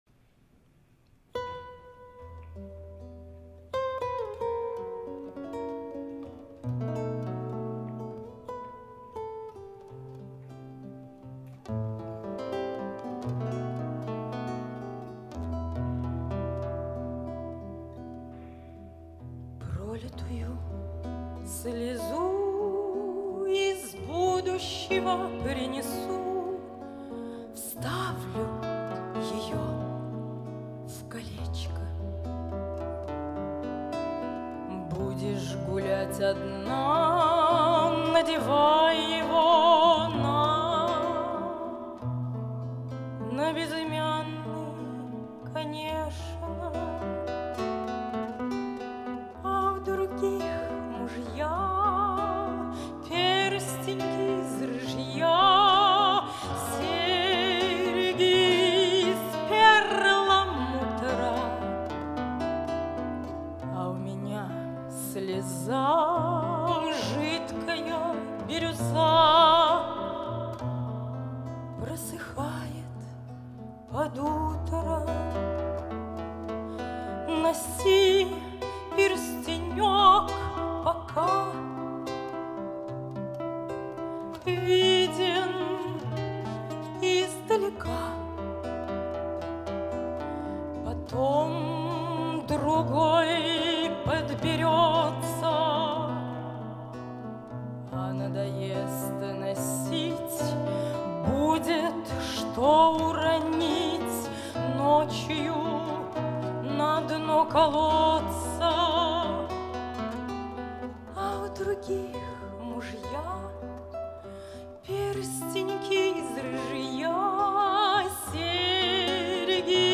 Жанр: Романсы
акустическая гитара
певица с волшебным голосом.
(звук сняла с видео на youtube)
Голос чистый и красивый.